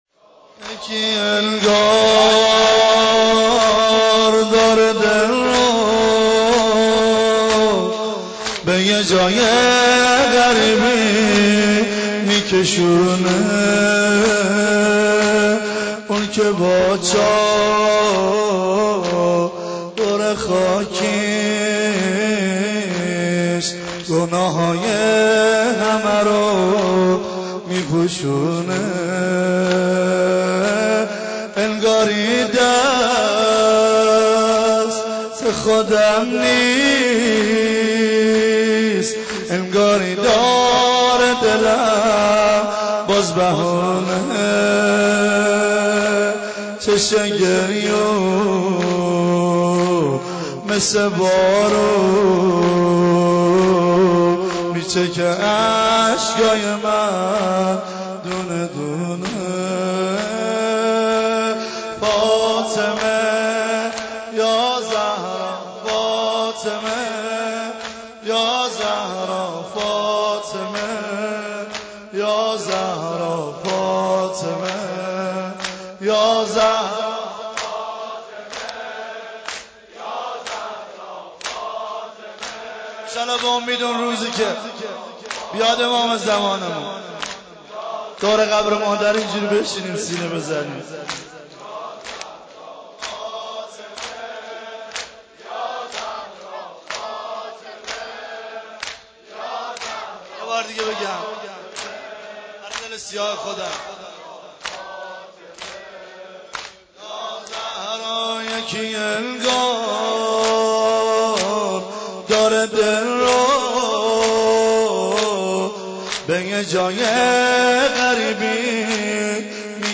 نوحه خوانی و سینه زنی به مناسبت شهادت حضرت زهرا(س)؛ «فاطمیه 90